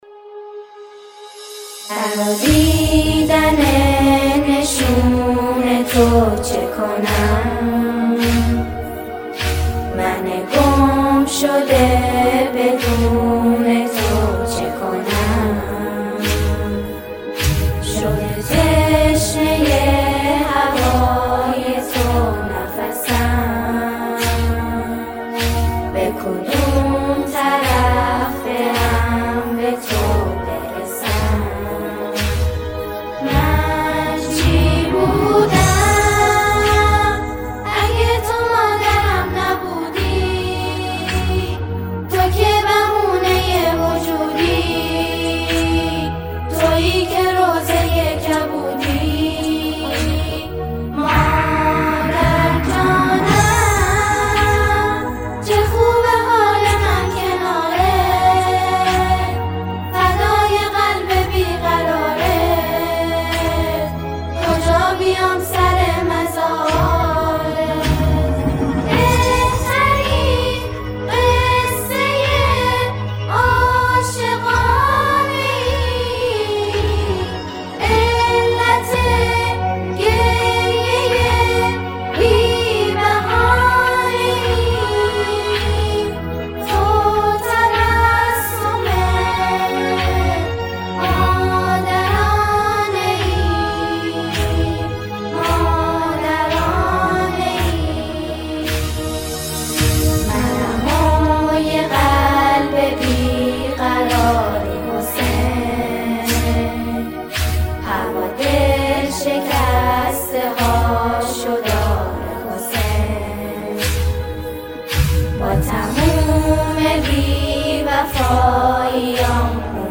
همخوانی شعری